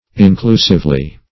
inclusively - definition of inclusively - synonyms, pronunciation, spelling from Free Dictionary Search Result for " inclusively" : The Collaborative International Dictionary of English v.0.48: Inclusively \In*clu"sive*ly\, adv. In an inclusive manner.